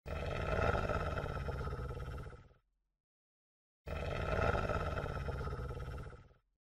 boru-kurt-hirlamasi.mp3